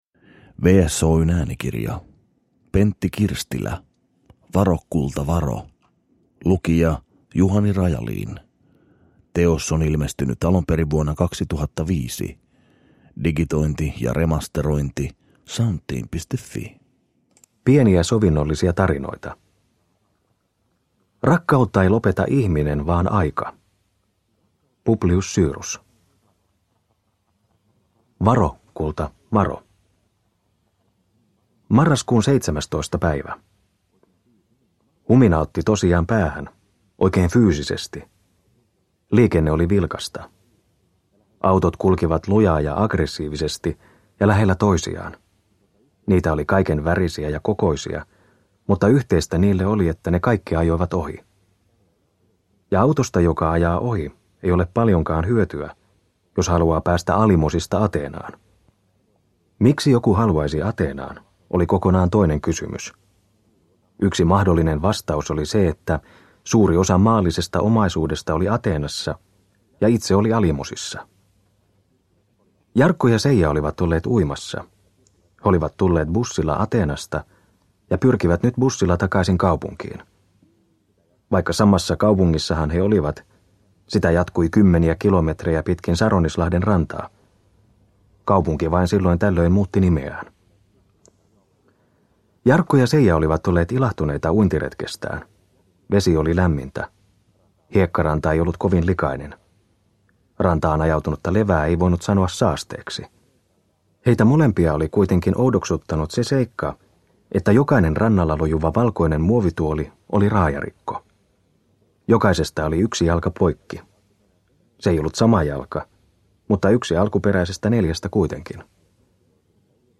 Varo, kulta, varo – Ljudbok – Laddas ner